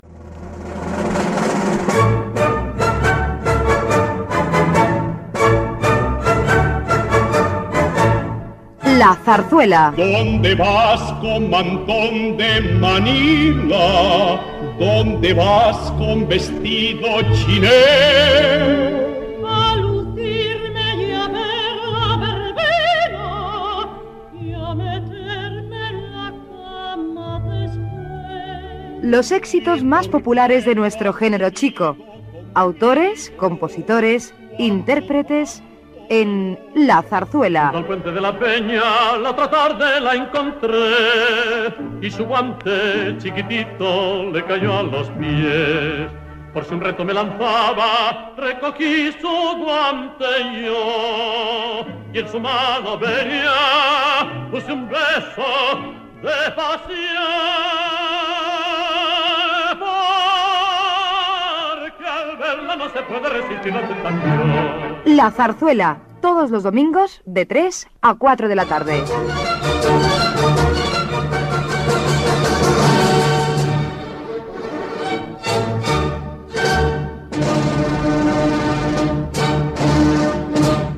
Careta del programa
Musical